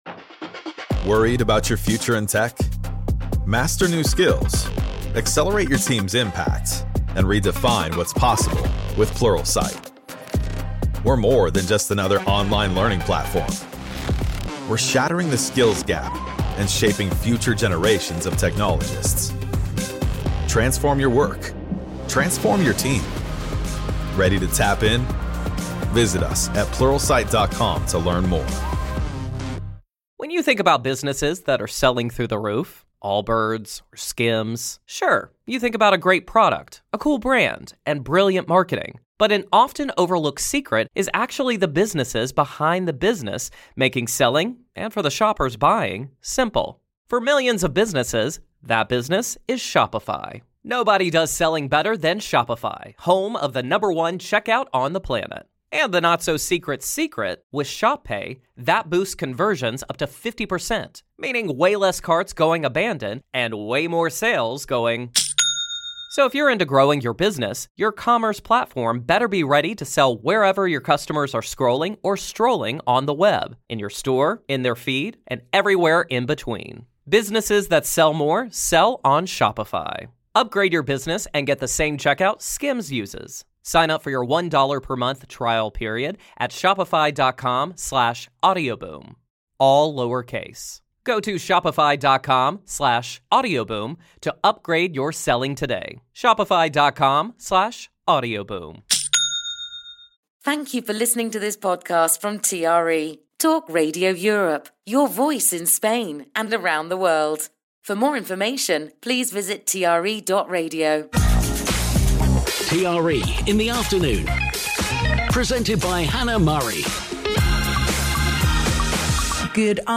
panel of guests